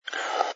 sfx_slurp_glass03.wav